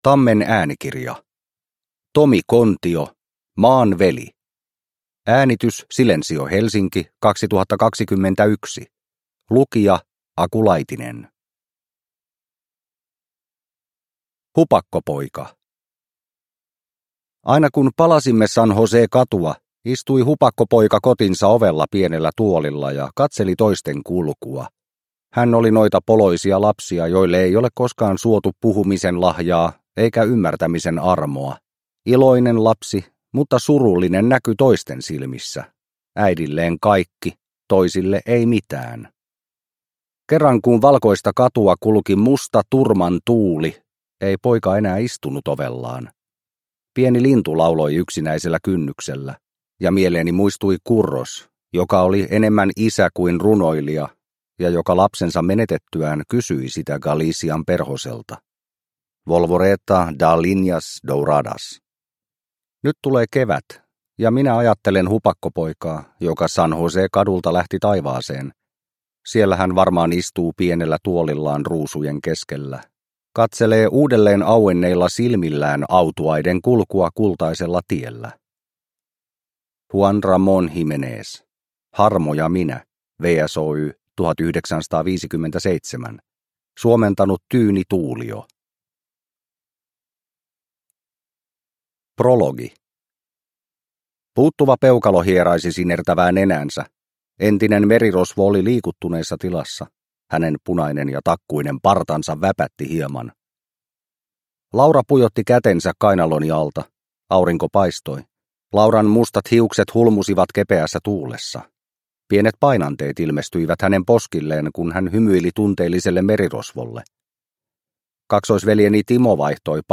Maan veli – Ljudbok – Laddas ner